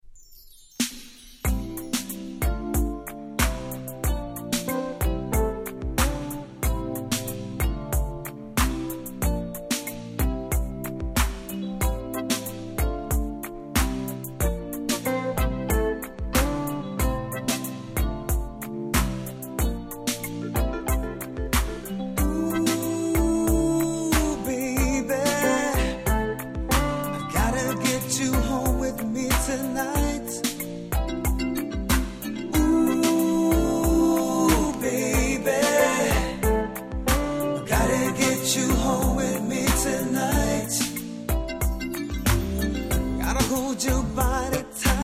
80's Soul